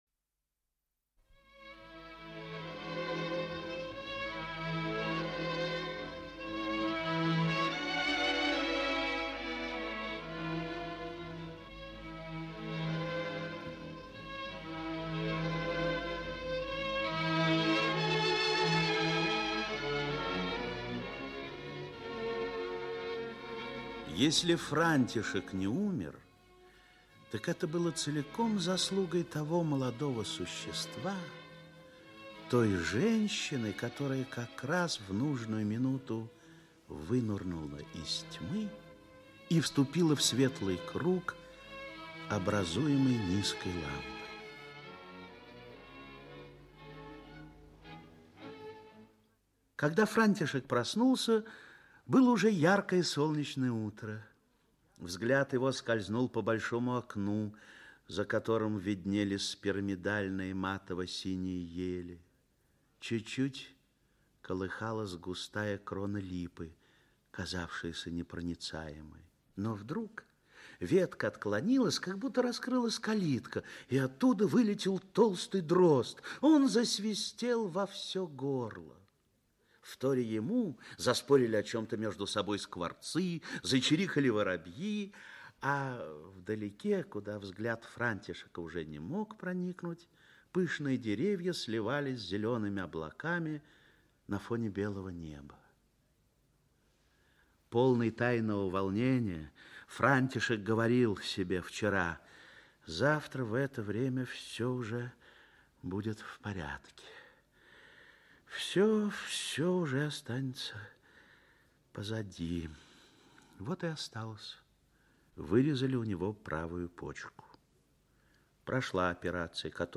Аудиокнига Сестра Алена | Библиотека аудиокниг
Aудиокнига Сестра Алена Автор Мария Пуйманова Читает аудиокнигу Актерский коллектив.